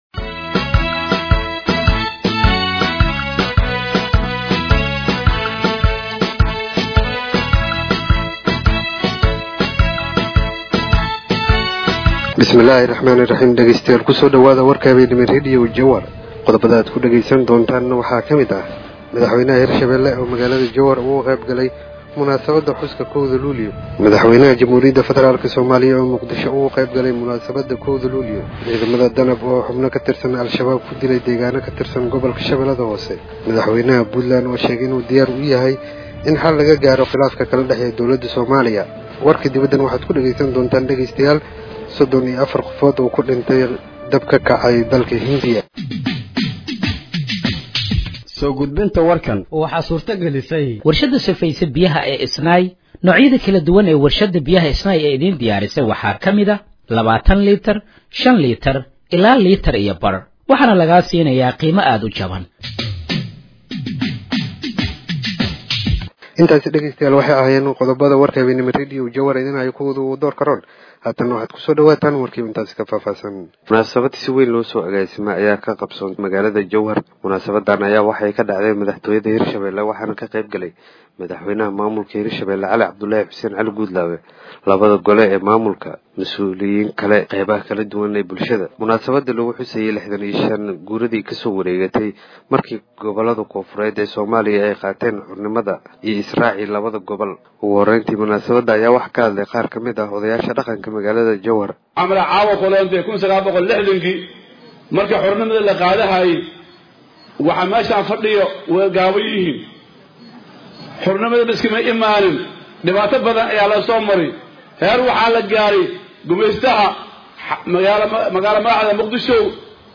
Halkaan Hoose ka Dhageeyso Warka Habeenimo ee Radiojowhar